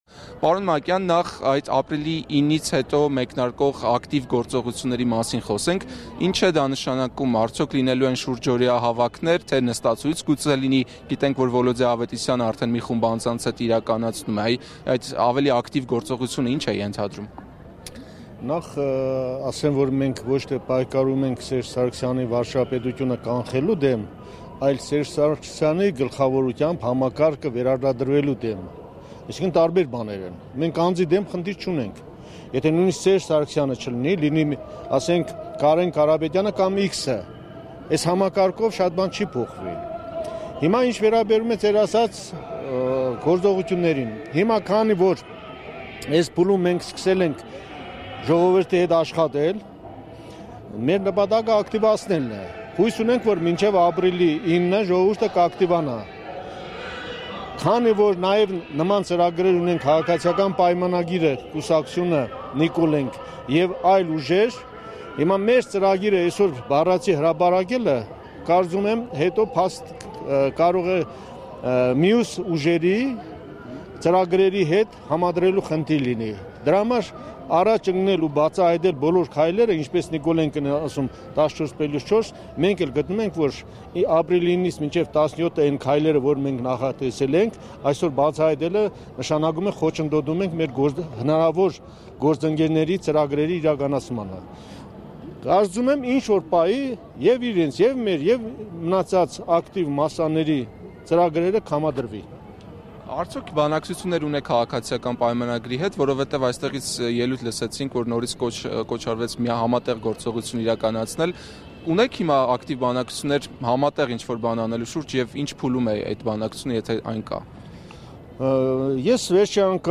Հարցազրույց